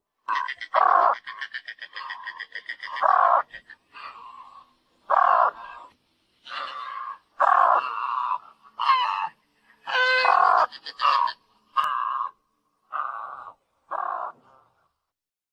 白鹭叫声